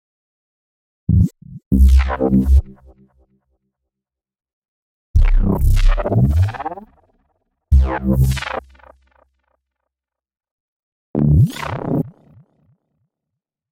140 阶梯低音回声
标签： 140 bpm Dubstep Loops Bass Synth Loops 2.31 MB wav Key : Unknown
声道立体声